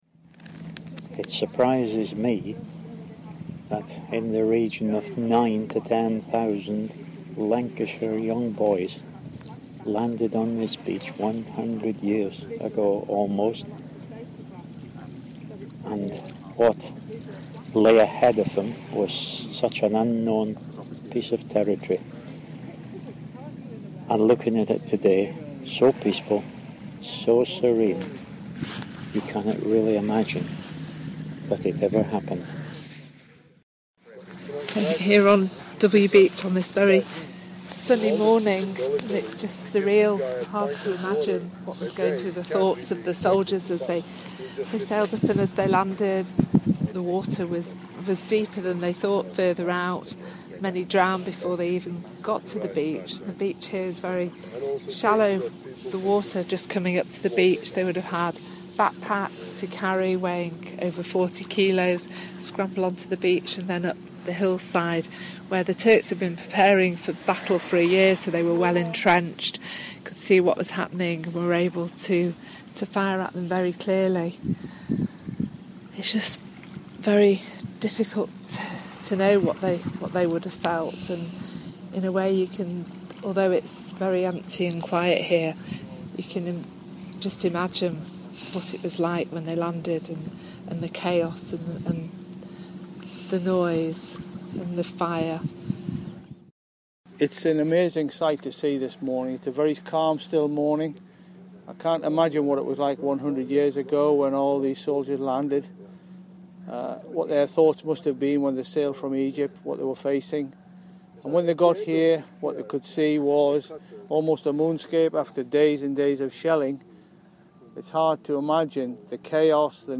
Gallipoli audio diary
To mark the anniversary, a delegation from the regiment's home town of Bury went to Gallipoli.